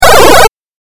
レトロゲーム （105件）
8bitダメージ12.mp3